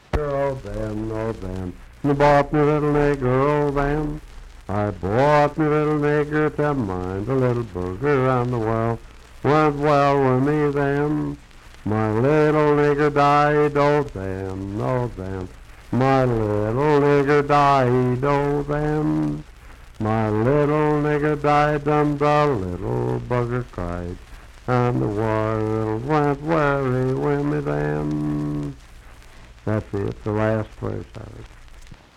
Unaccompanied vocal music
Minstrel, Blackface, and African-American Songs
Voice (sung)
Marion County (W. Va.), Fairview (Marion County, W. Va.)